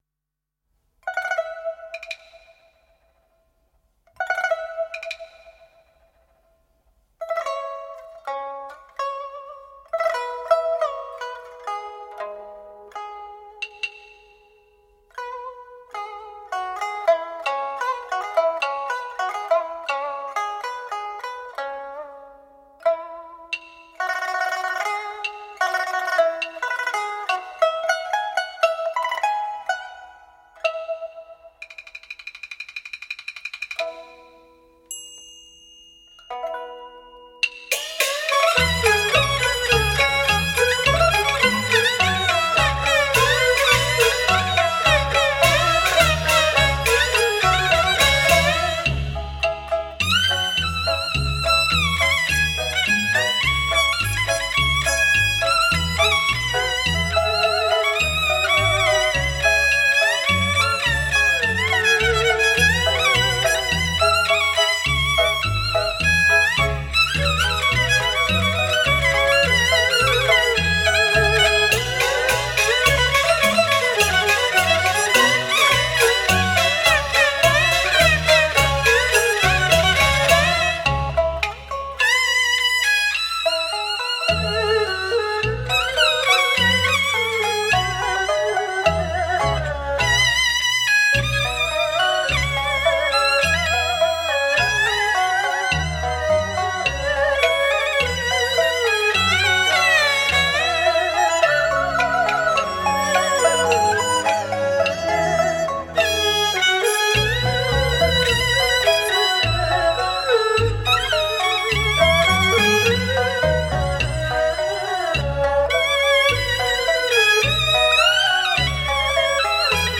千古绝唱 音效非凡 国乐大师倾情演绎
全新编配 国乐名家倾情演绎 披沙沥金展现梨园华章 领会戏曲真奥妙
京剧